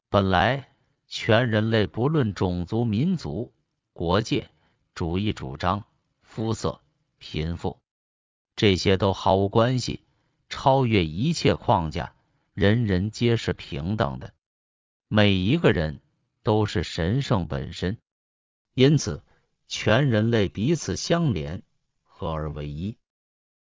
本来の在り方宣言ー中国語(男性).mp3
※ 発音基準：普通话（標準中国語）